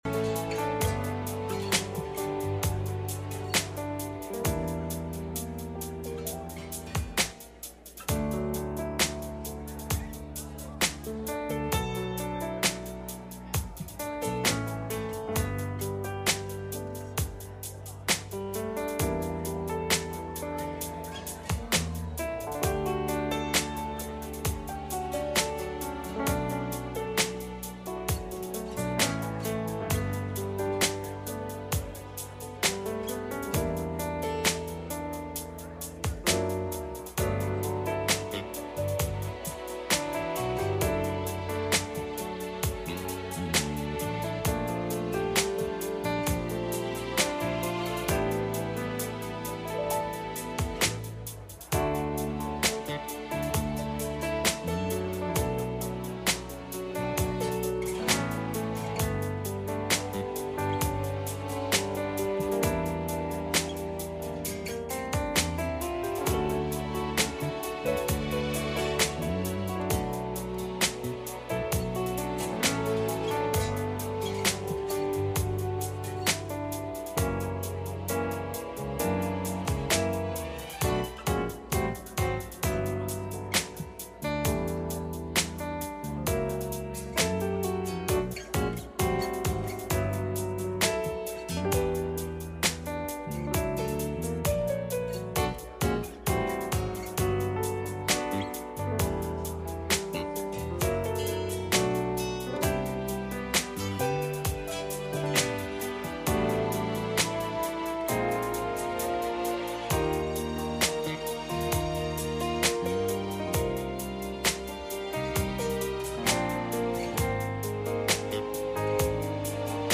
Passage: Mark 4:34 Service Type: Sunday Morning